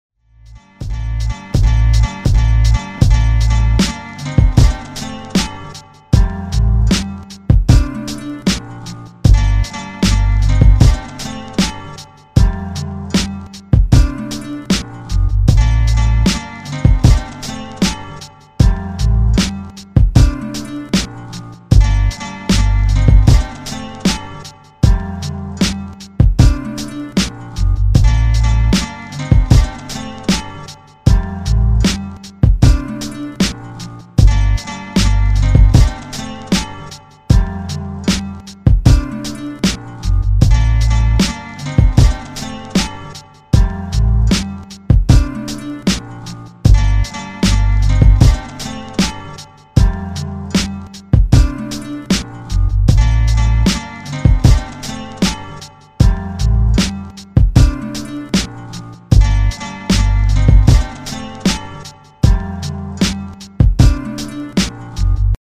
straight banger